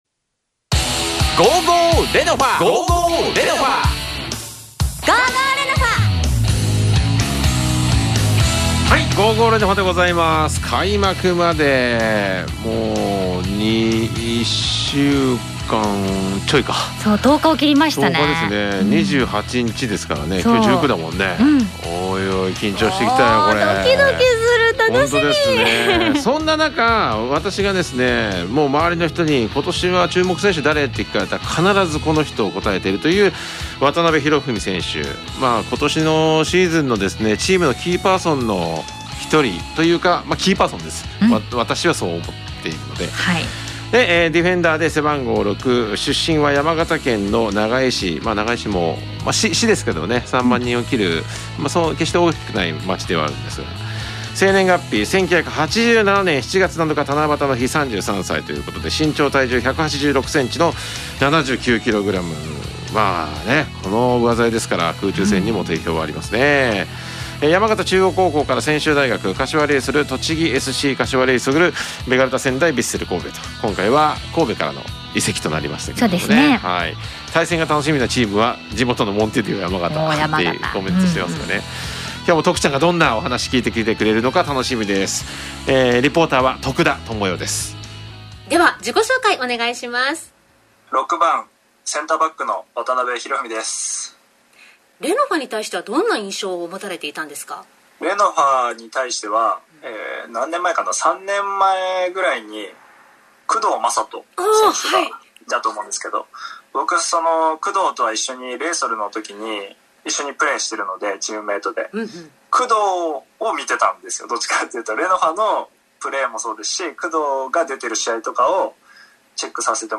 ※インタビューは通信会議アプリを使って収録したものです。